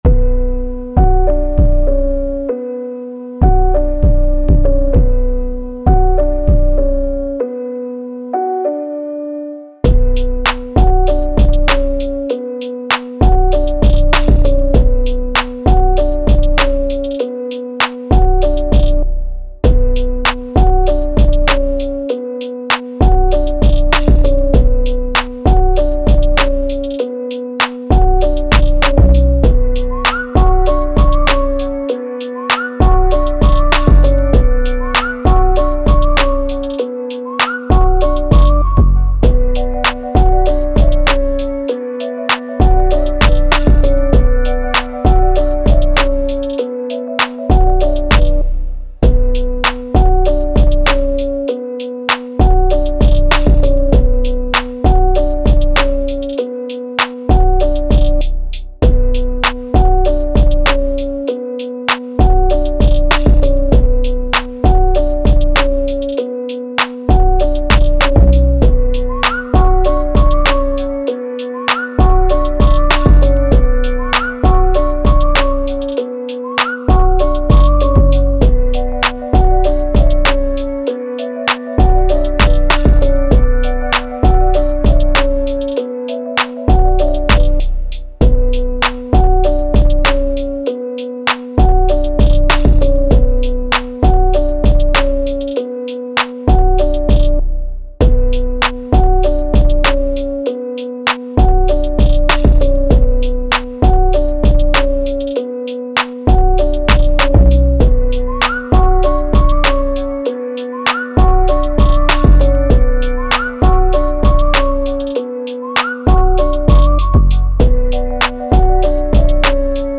Hip Hop
B Min